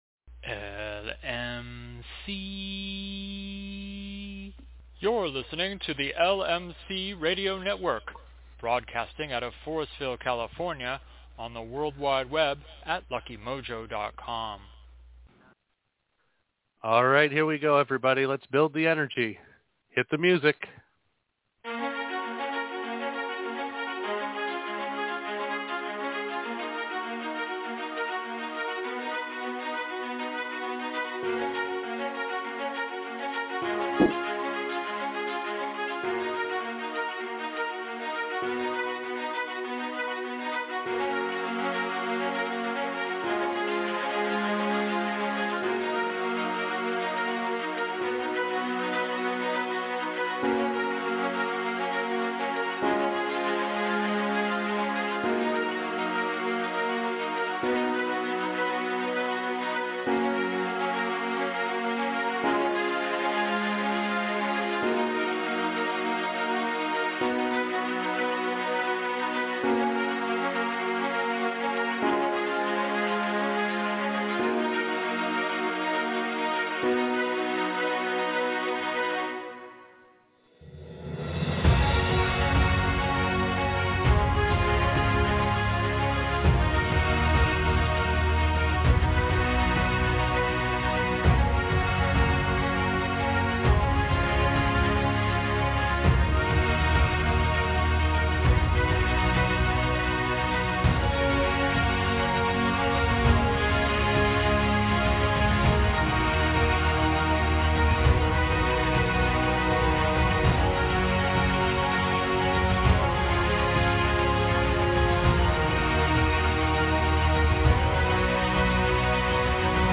We begin this show with an interview of our guest followed by a discussion on the use of herbs in spellwork. So, how does one use herbs in their spiritual and magical practice?